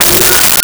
Megaphone Feedback 06
Megaphone Feedback 06.wav